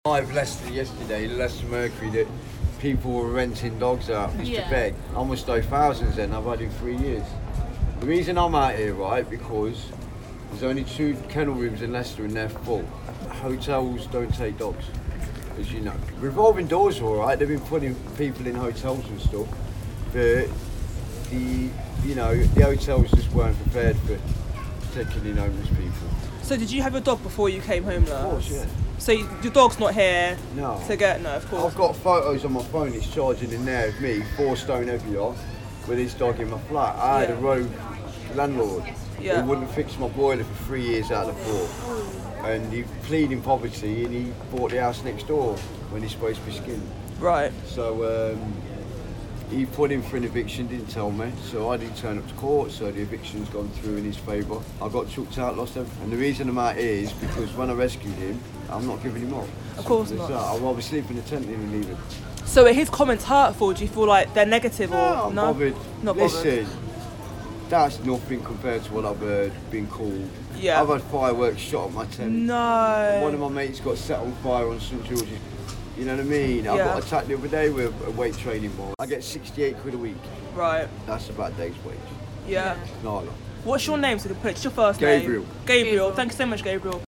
Audio Vox Pop: Should you give money to the homeless?
homeless-dog-interview.mp3